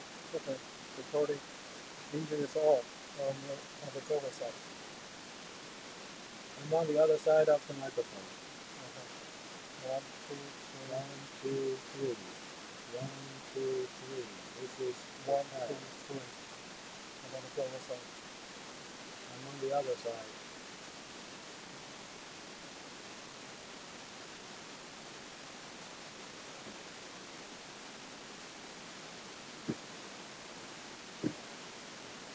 Demo – In-Vehicle Voice Separation:
The regular microphone output is mixed two-speaker’s voice with background noise:
Audio-1_Regular_Mic_Two_Speakers_cut.wav